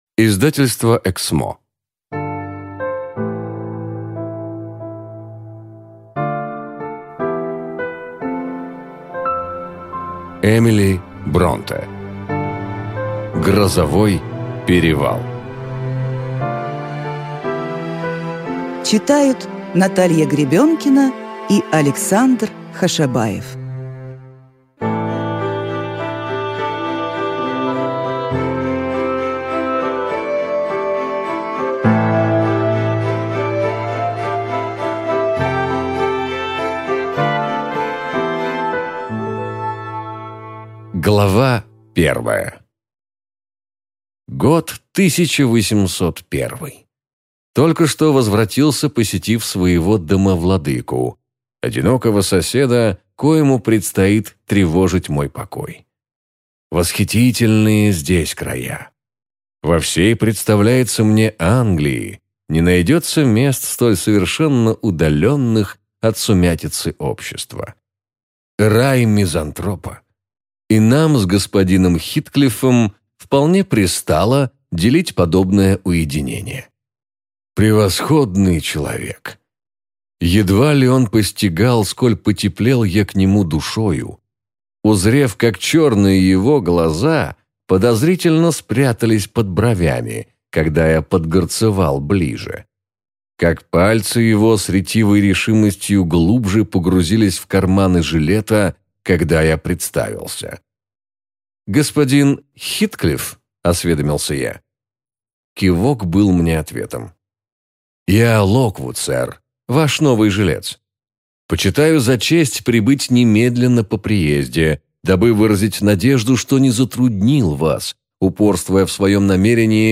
Аудиокнига Грозовой перевал | Библиотека аудиокниг